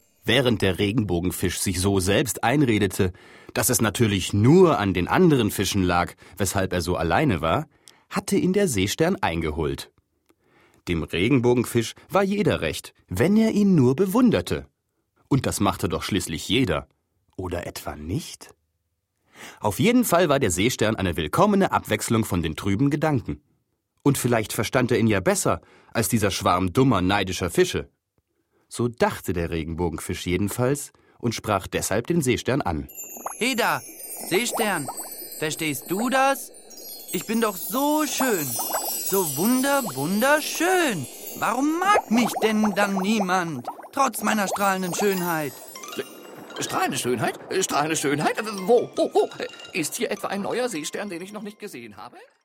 Ravensburger Der Regenbogenfisch - Folge 1: und die Glitzerschuppen ✔ tiptoi® Hörbuch ab 3 Jahren ✔ Jetzt online herunterladen!